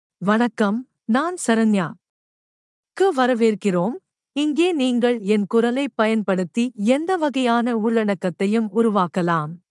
FemaleTamil (Sri Lanka)
Saranya — Female Tamil AI voice
Saranya is a female AI voice for Tamil (Sri Lanka).
Voice sample
Listen to Saranya's female Tamil voice.
Saranya delivers clear pronunciation with authentic Sri Lanka Tamil intonation, making your content sound professionally produced.